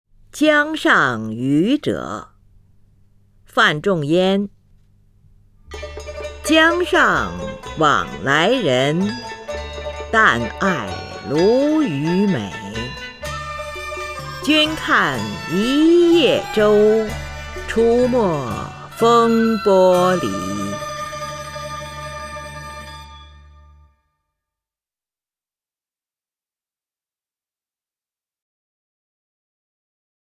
林如朗诵：《江上渔者》(（北宋）范仲淹) （北宋）范仲淹 名家朗诵欣赏林如 语文PLUS